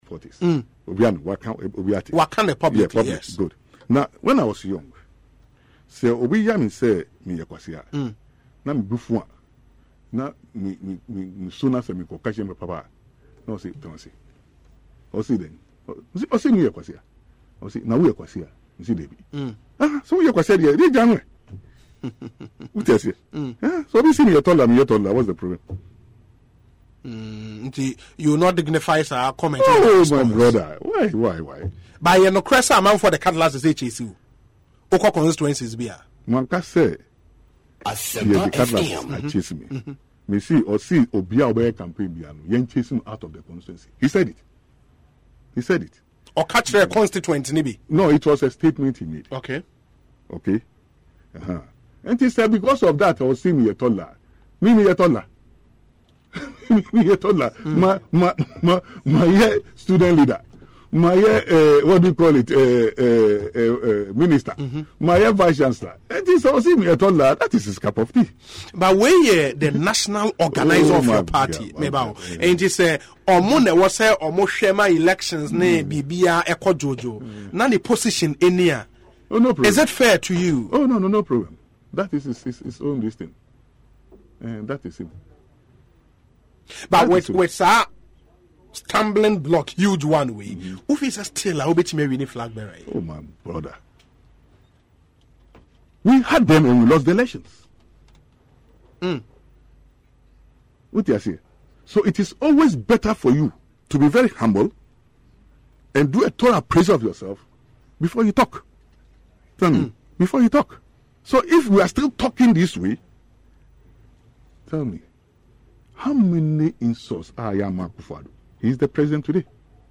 But speaking on Asempa FM’s Ekosii Sen Monday, Professor Alabi wondered why Kofi Adams could make such proclamation.